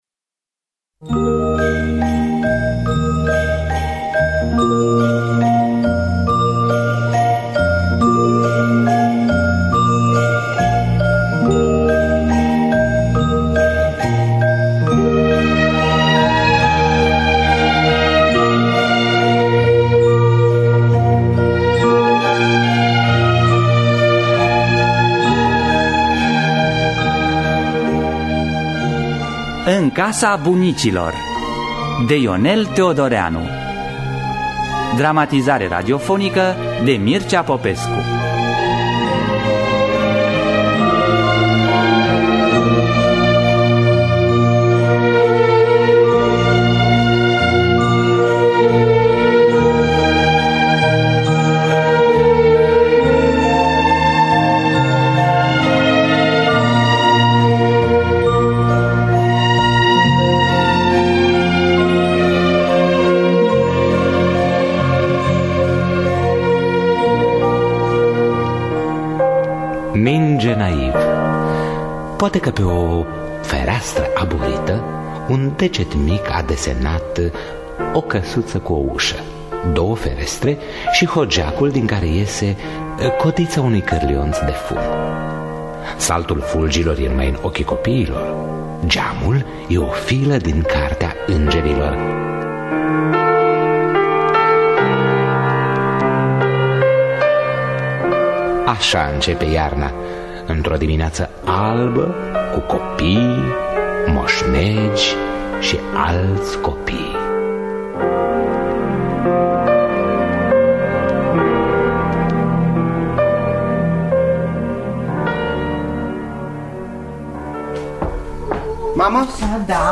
Dramatizarea radiofonicã